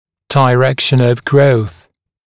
[dɪ’rekʃ(ə)n əv grəuθ] [daɪ-][ди’рэкш(э)н ов гроус] [дай-]направление роста